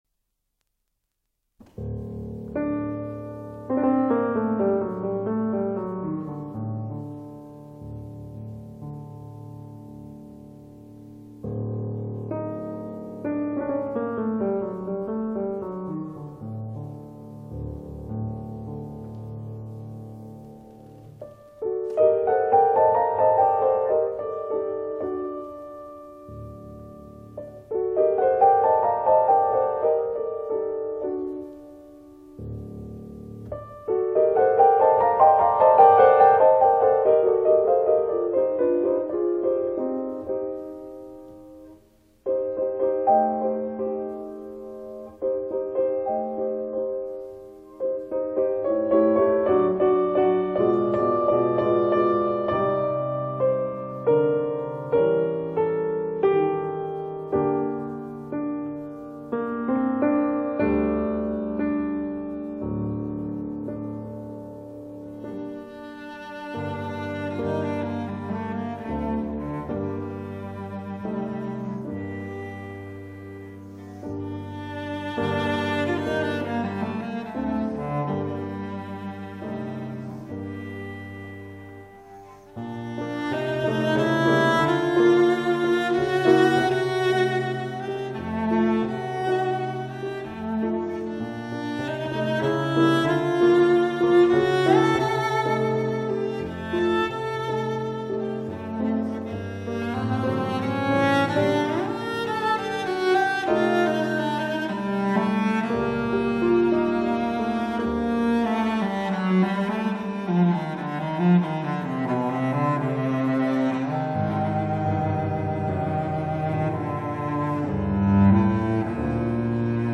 D. Popper - Serenade orientale op. 18 för violoncell och piano